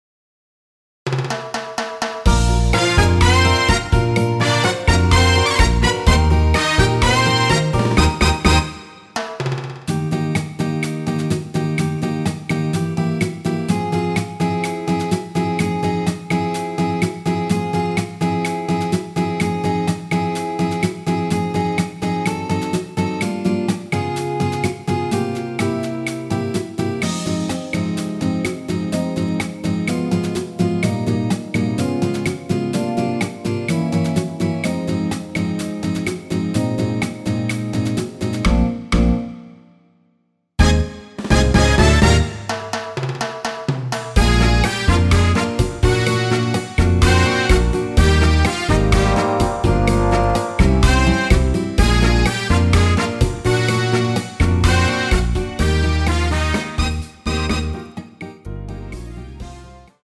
원키에서(+2)올린 MR입니다.
Eb
앞부분30초, 뒷부분30초씩 편집해서 올려 드리고 있습니다.
중간에 음이 끈어지고 다시 나오는 이유는